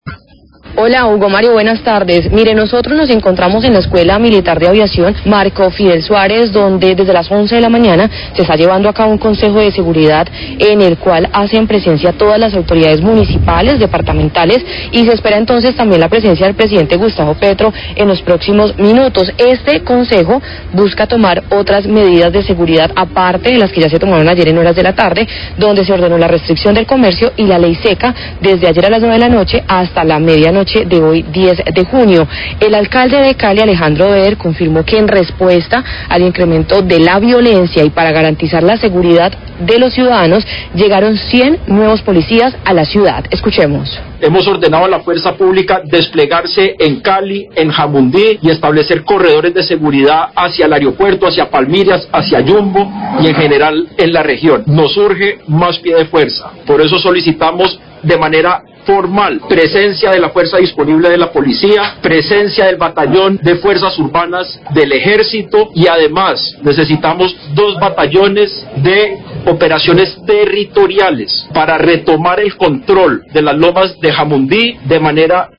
Radio
Concejo extrordinario en la escuela Militar de Aviación para tratar el tema de ataques terroristas ocurridos el día anterior.